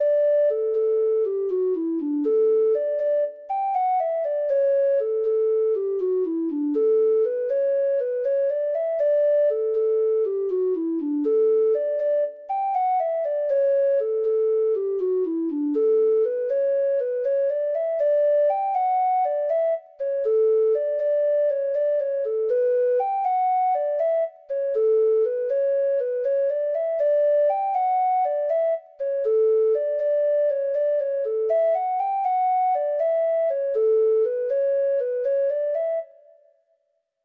Traditional Music of unknown author.
Irish Slip Jigs